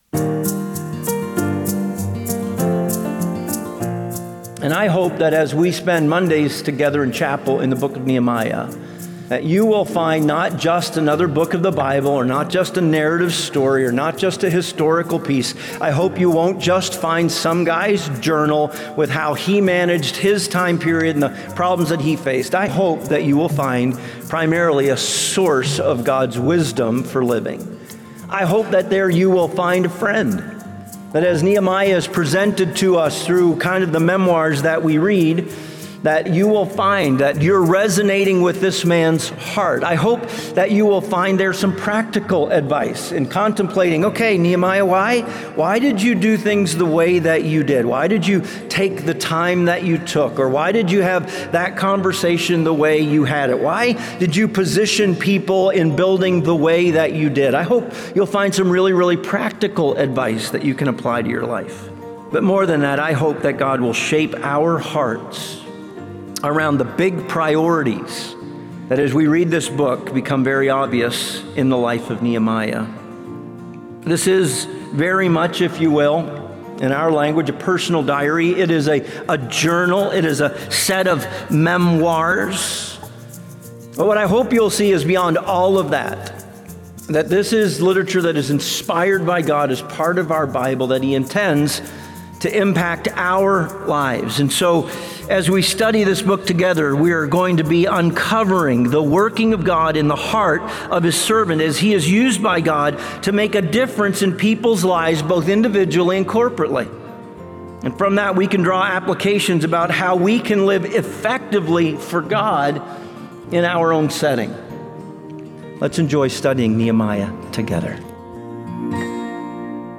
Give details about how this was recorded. at BJU chapel.